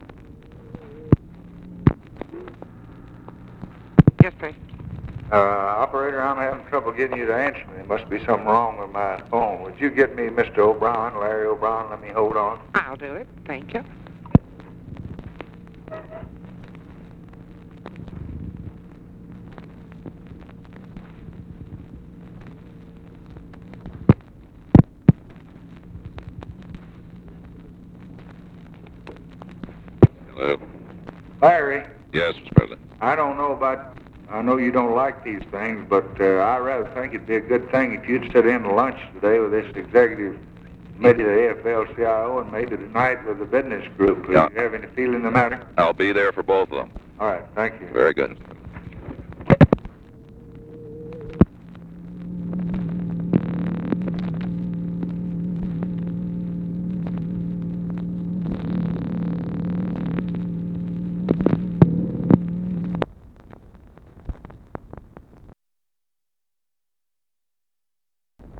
Conversation with LARRY O'BRIEN, January 7, 1964
Secret White House Tapes